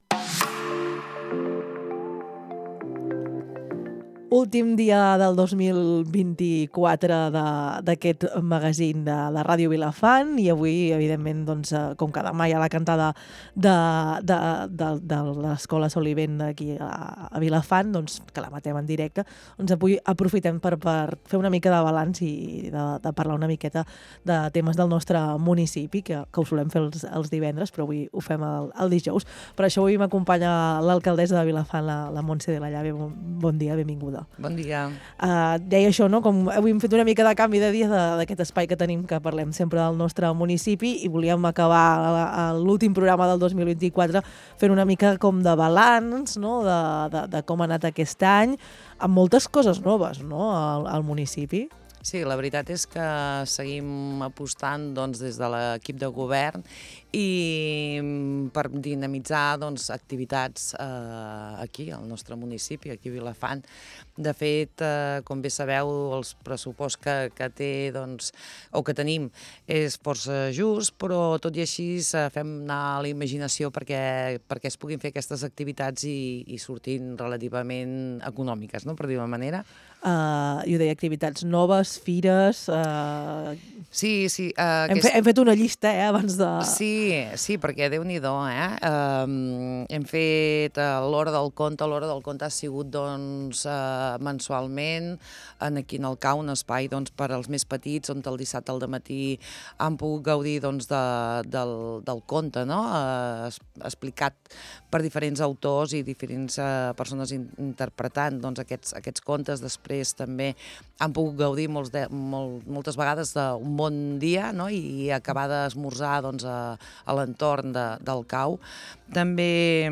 A Les Veus del Matí, l’alcaldessa Montse de la Llave fa un repàs de com ha anat a Vilafant aquest 2024 i de com seran les festes al nostre municipi. Ha acabat desitjant-nos un bon Nadal i Feliç 2025.
LVDM - ENTREVISTA - MONTSE DE LA LLAVE NADAL 18 DESEMBRE 24~0.mp3